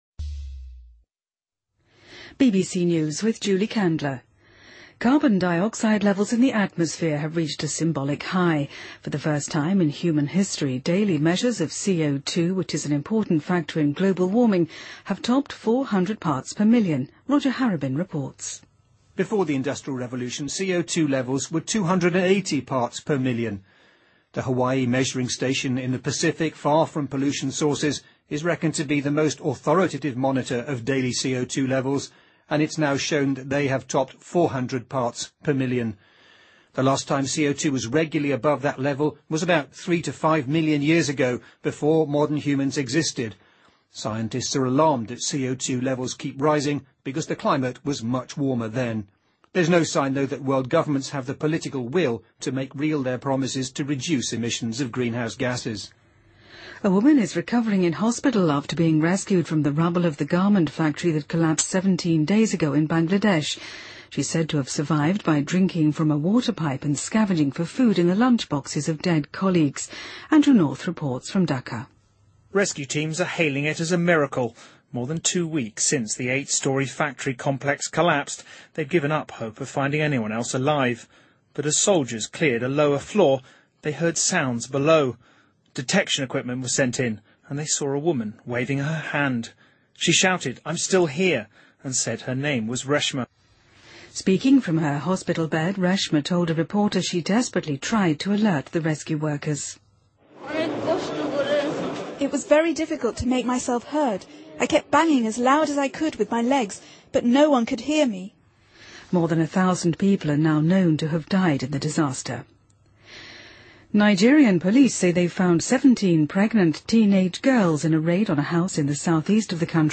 BBC news,2013-05-11